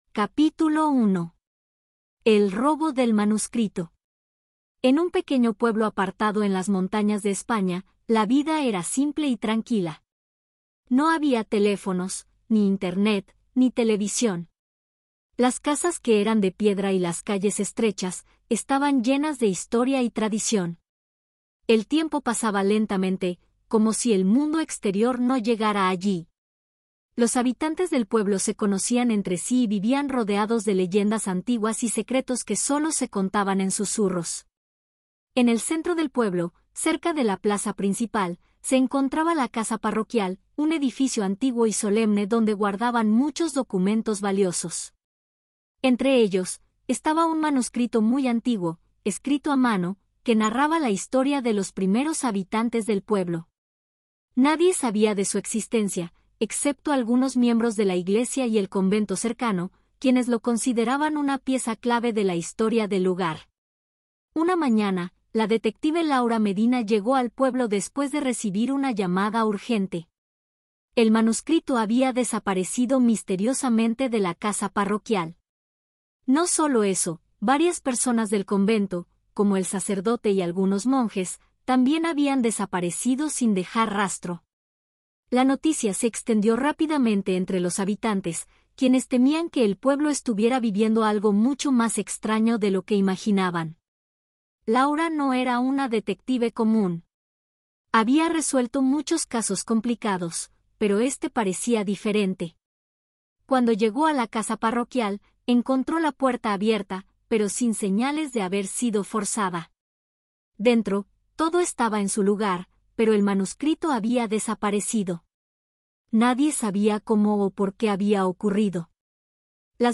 AudioBook El misterio del manuscrito B2-C1 - Hola Mundo